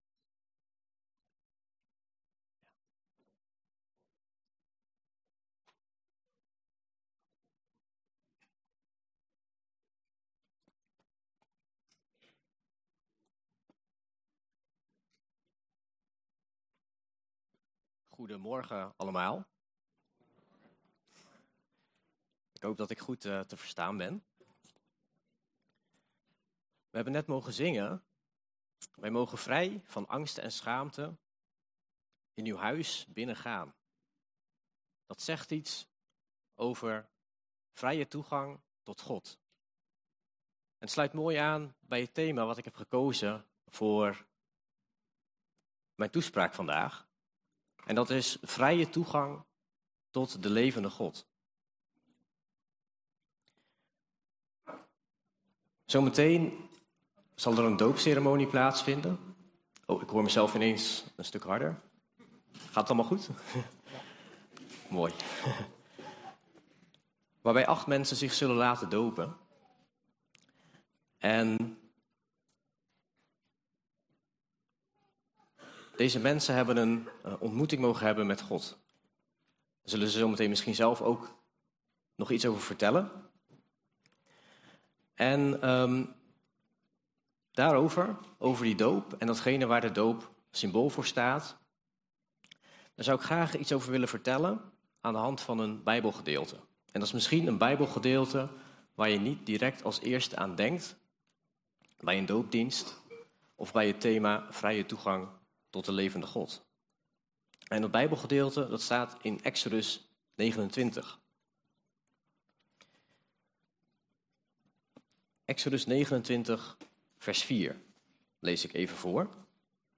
Toespraak 3 november: doopdienst - De Bron Eindhoven